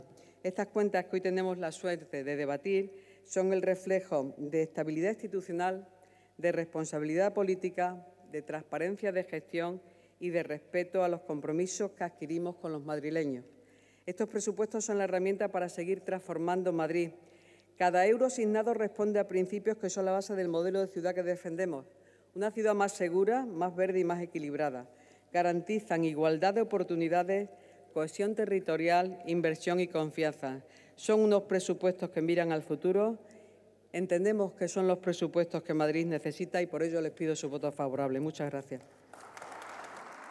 Hidalgo interviene en el Pleno extraordinario del 22 diciembre de 2025
AUDIO-Engracia.-Presupuestos-Generales-Ayuntamiento-de-Madrid-26.-Resumen-2.mp3